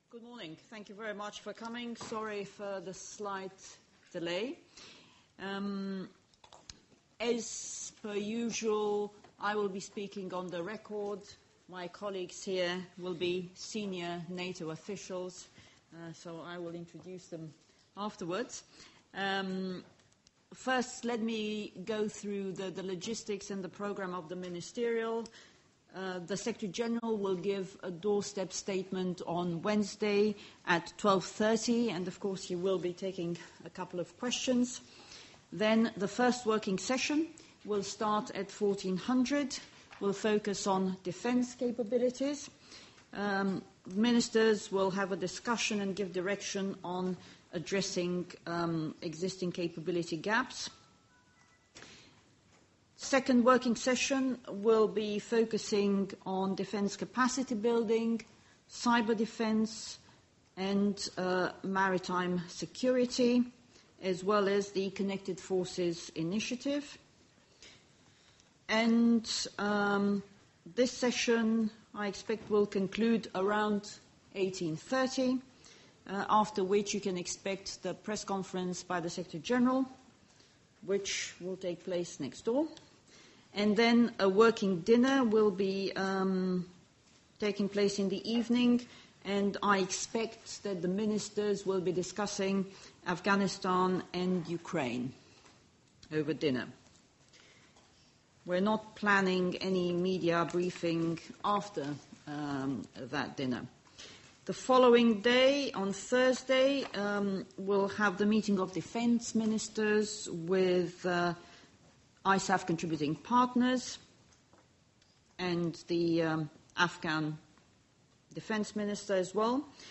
Audio Opening remarks by NATO Spokesperson Oana Lungescu at the pre-ministerial press briefing 24 Feb. 2014 | download mp3 From the event Opening remarks at the pre-ministerial briefing by the NATO Spokesperson, Oana Lungescu 24 Feb. 2014